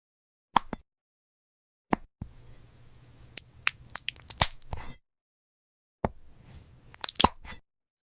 Cutting Jelly Lip Balm 👀 Sound Effects Free Download